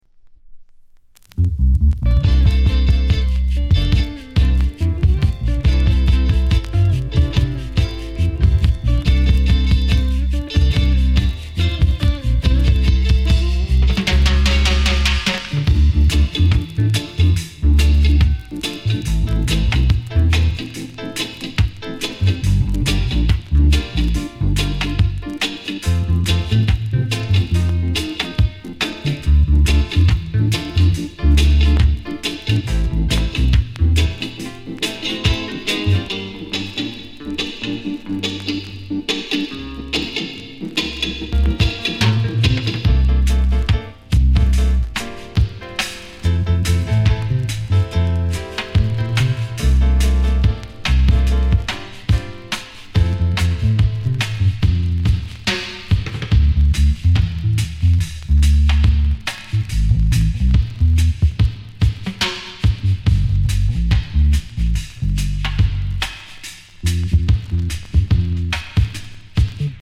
JA LOVERS ROCK！